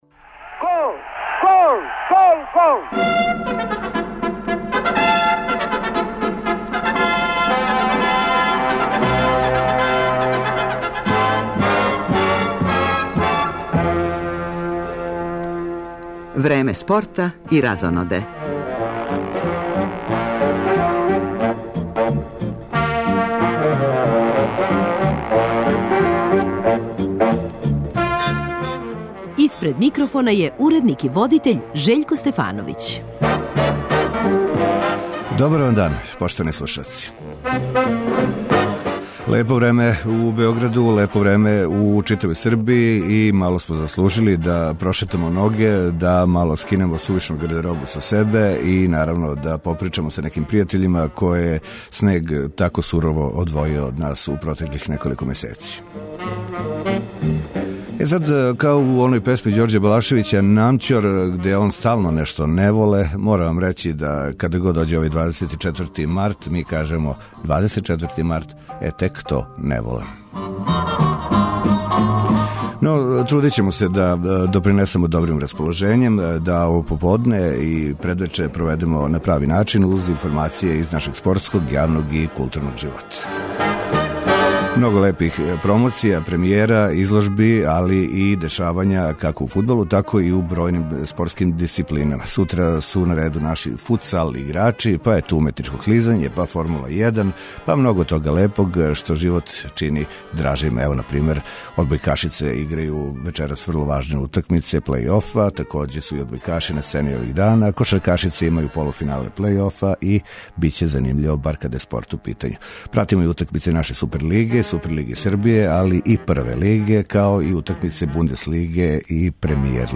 У оквиру породичног магазина пратимо друга полувремена утакмица 20. Кола Супер лиге Србије, које се играју у поподневном термину, као и први део сусрета између Црвене звезде и Борца. Такође, извештавамо о кретањима резултата на утакмицама немачког и енглеског фудбалског шампионата.